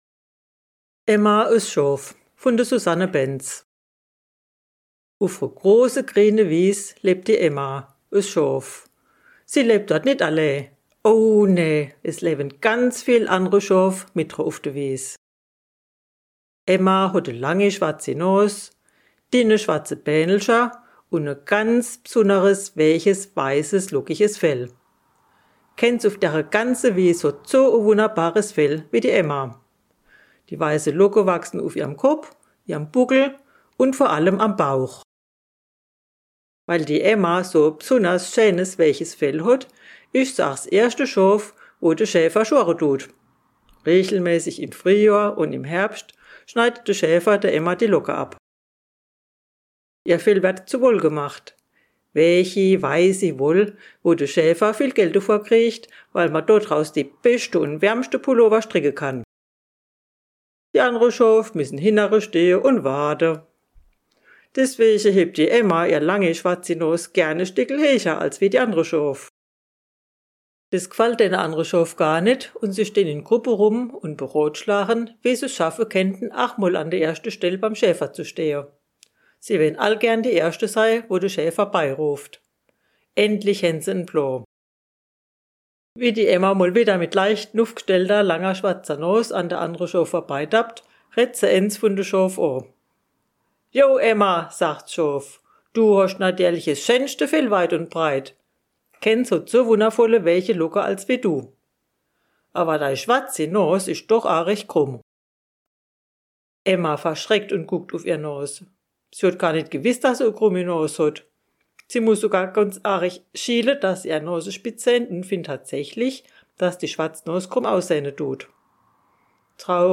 Emma, das Schaf - Dialekt Pfalz
Emma, das Schaf erzählt aus der Pfalz